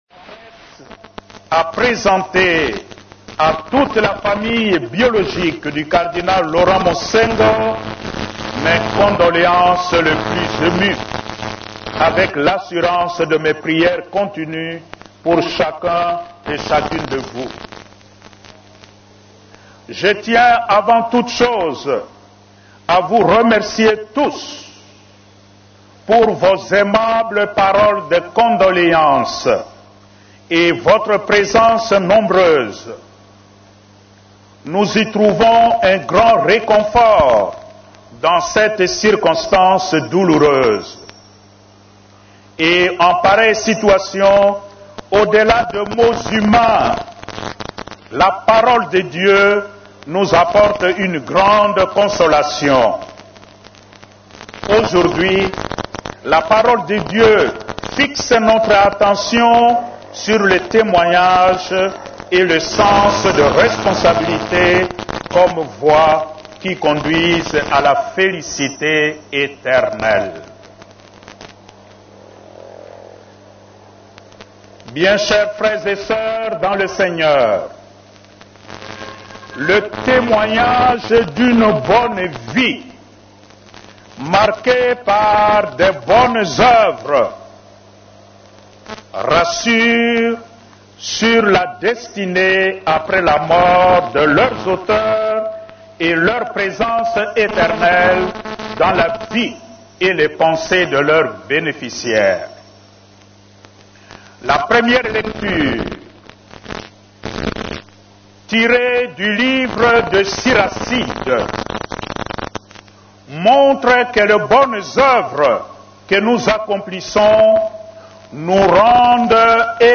Dans son homélie ce mardi 20 juillet au Palais du peuple, où la dépouille mortelle du Cardinal Laurent Monsengwo Pasinya est exposée, le Cardinal Fridolin Ambongo a plaidé pour la paix et une justice distributive en RDC.